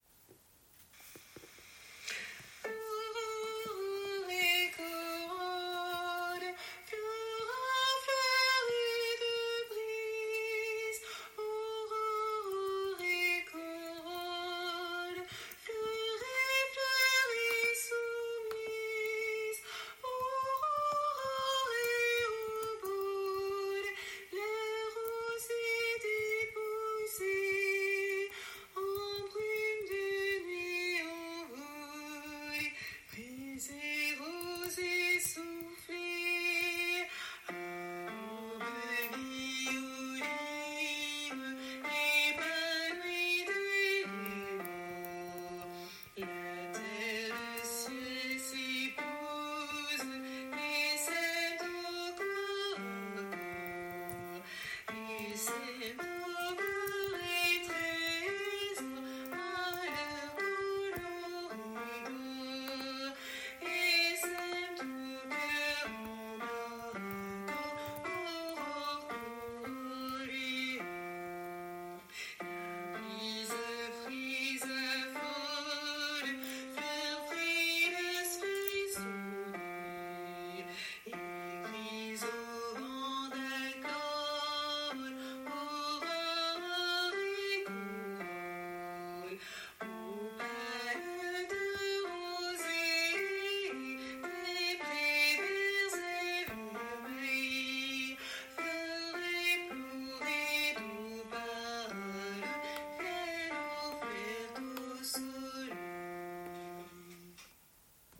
- Oeuvre pour choeur à 4 voix mixtes (SATB)
MP3 versions chantées
Basse